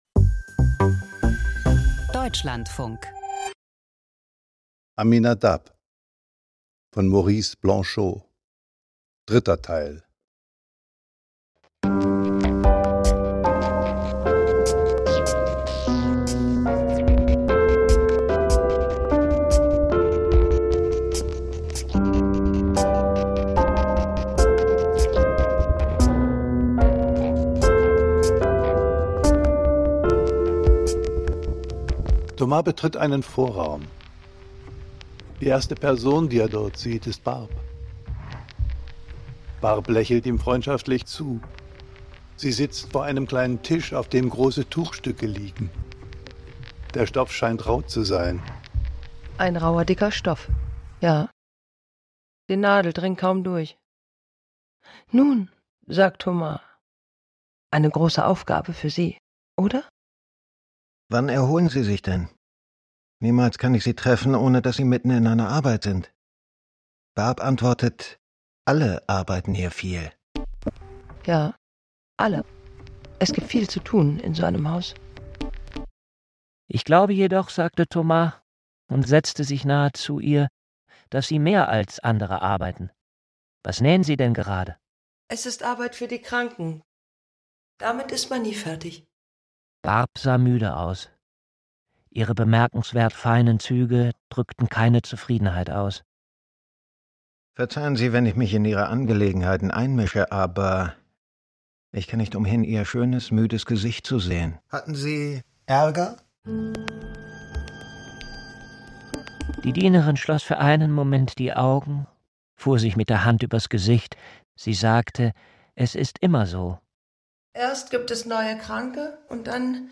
Kinderhörspiel